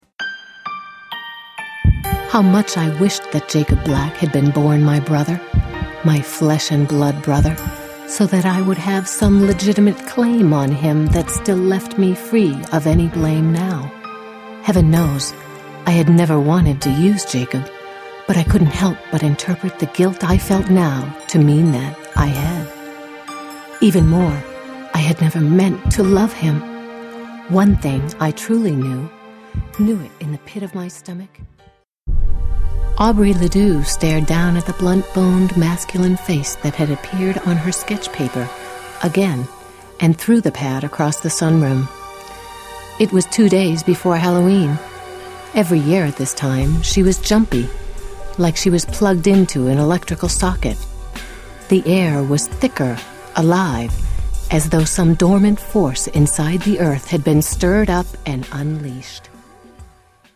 Female
Adult (30-50), Older Sound (50+)
Audiobooks
All our voice actors have professional broadcast quality recording studios.